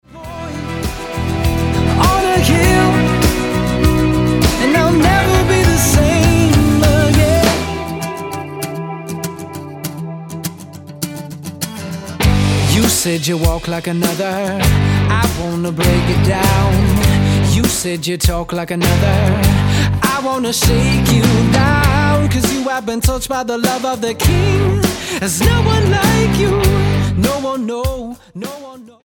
American singer/songwriter
Style: Pop